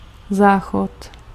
Ääntäminen
IPA: [ka.bi.nɛ]